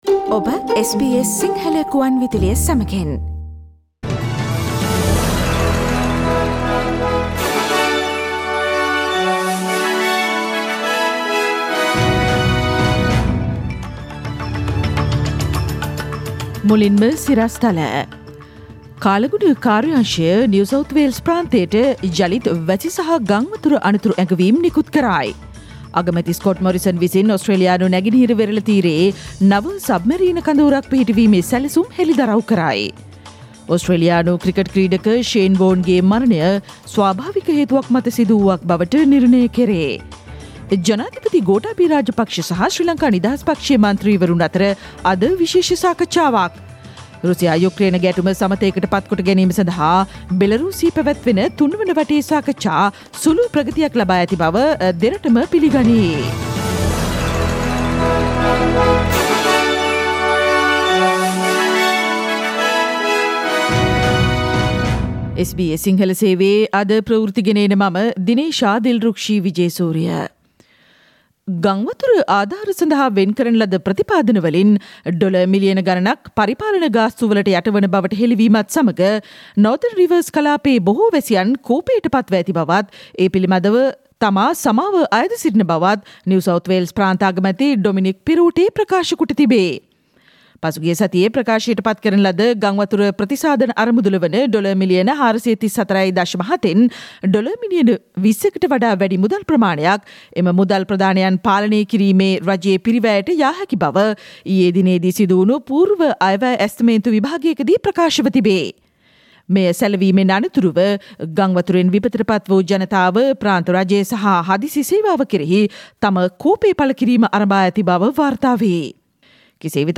ඉහත චායාරූපය මත ඇති speaker සලකුණ මත click කොට මාර්තු 8 වන අඟහරුවාදා SBS සිංහල ගුවන්විදුලි වැඩසටහනේ ප්‍රවෘත්ති ප්‍රකාශයට ඔබට සවන්දිය හැකියි.